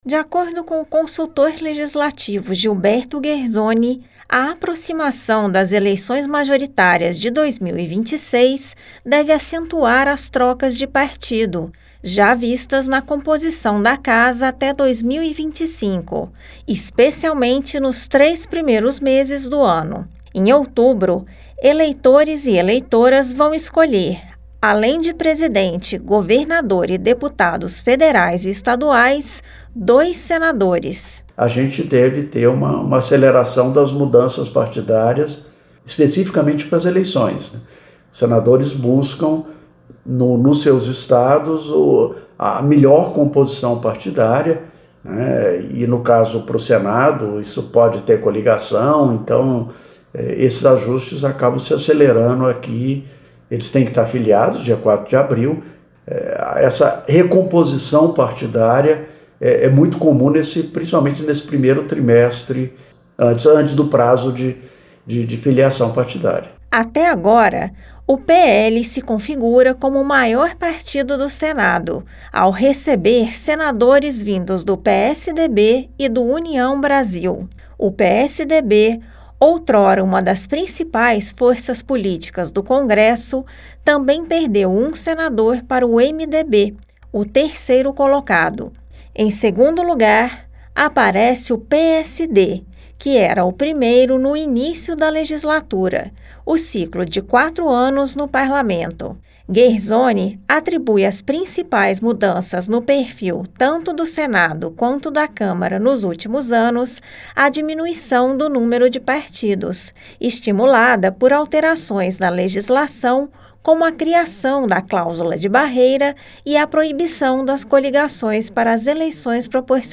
Análise